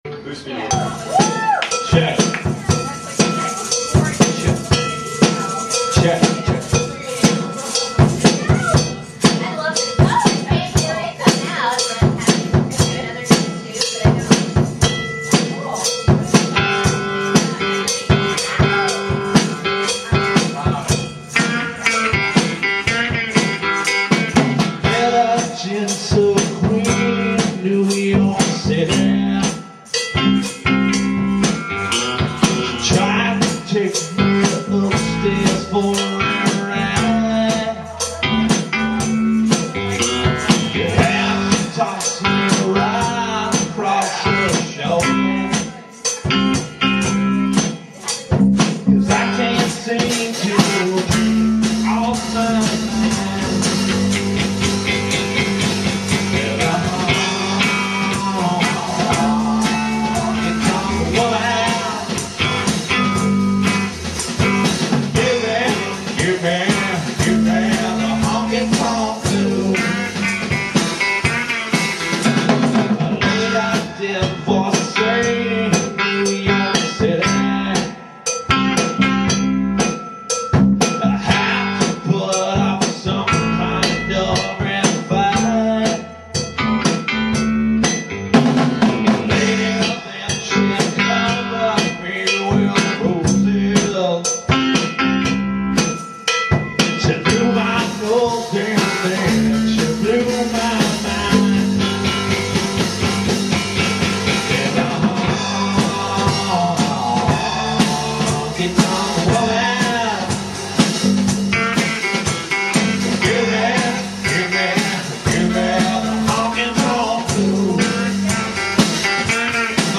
drums, vocals
vocals, guitar
bass, vocals
guitar, vocals, keyboard